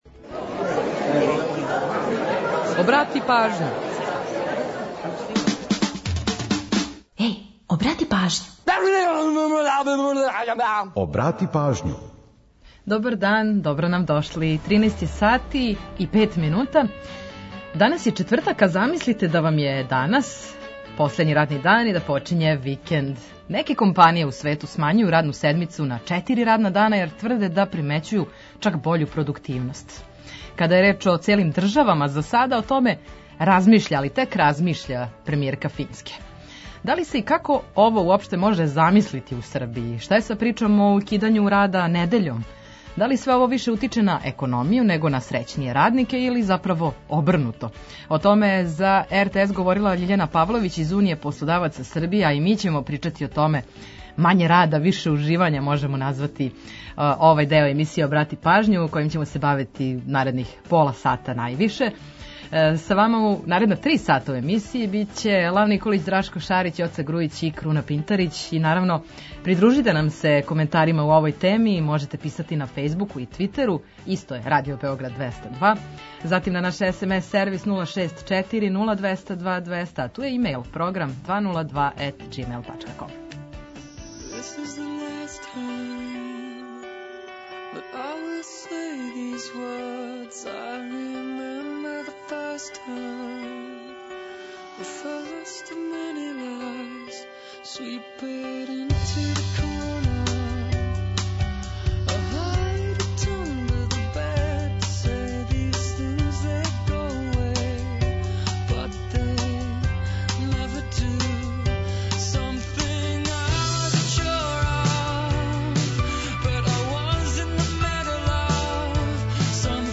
Ту су и музичке теме којима подсећамо на приче иза песама и рођендане музичара и албума, уз пола сата резервисаних само за музику из Србије и региона.